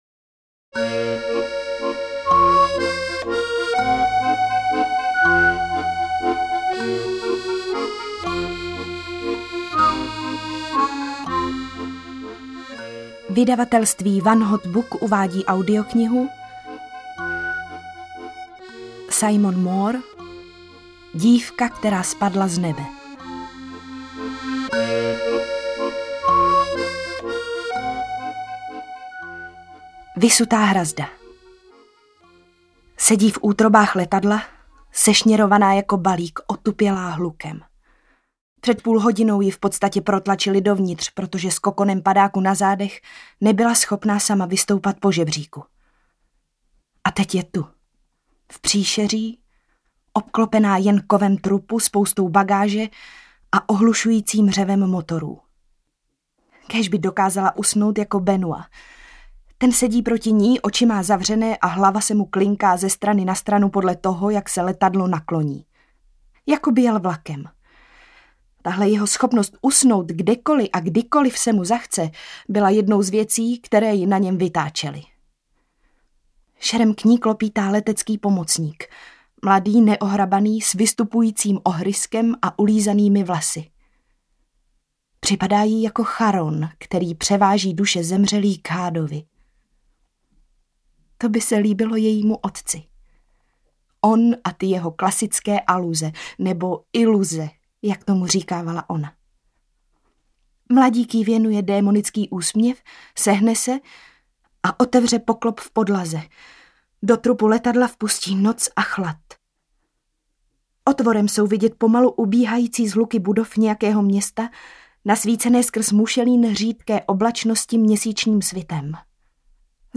Dívka, která spadla z nebe audiokniha
Ukázka z knihy